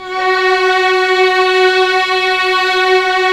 Index of /90_sSampleCDs/Roland LCDP13 String Sections/STR_Violins III/STR_Vls6 f amb